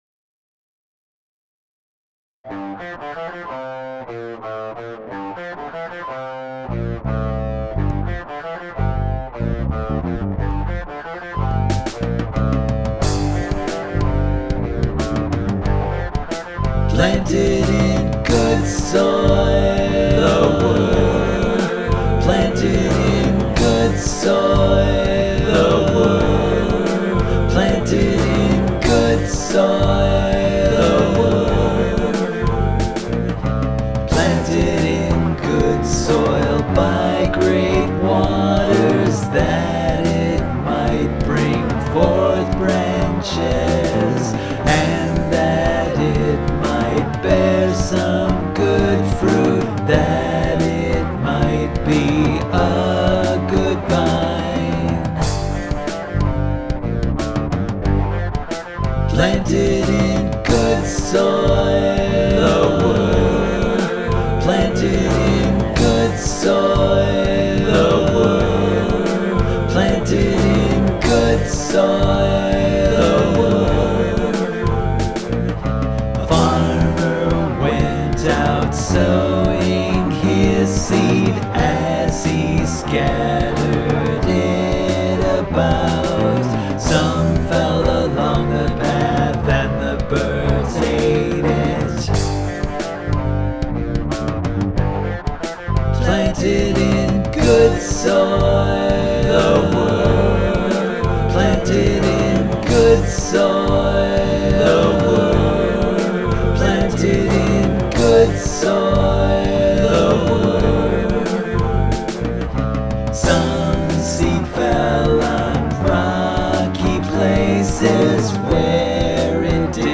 Vocals, Guitars, Bass